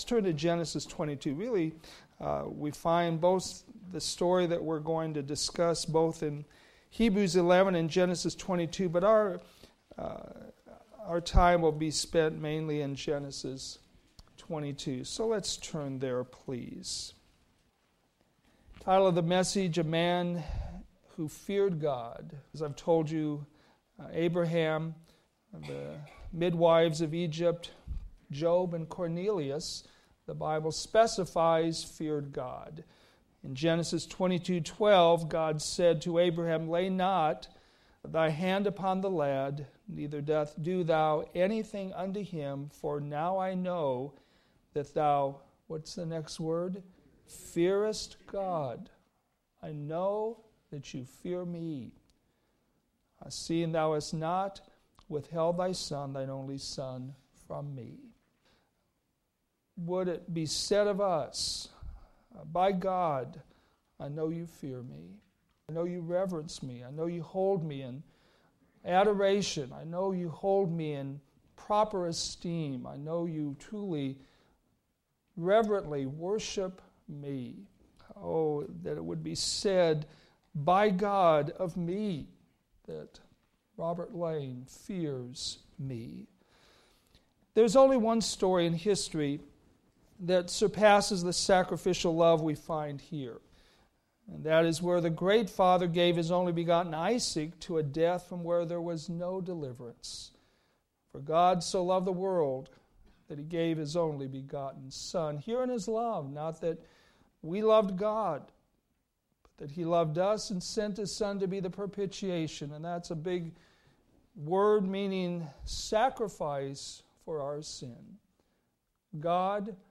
Sermons based on Old Testament Scriptures